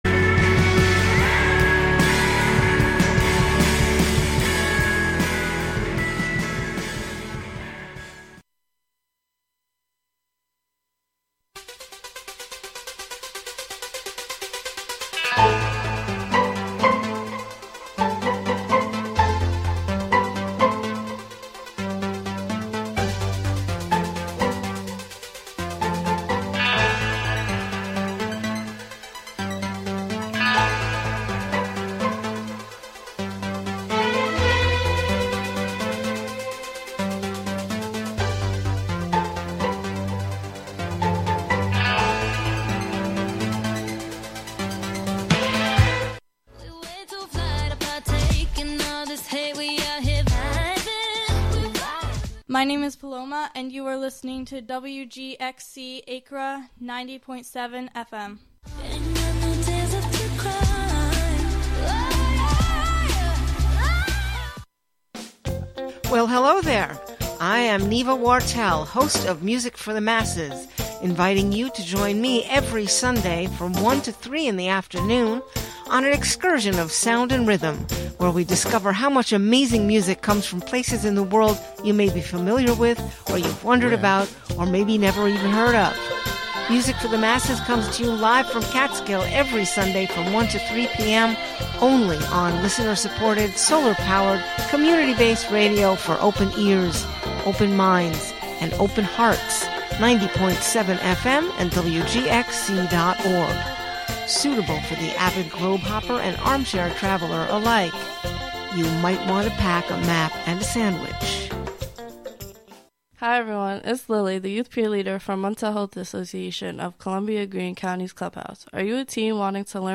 Music, talk, and schtick, just like any variety show. One difference, though, is the Democratic Socialists also confront power.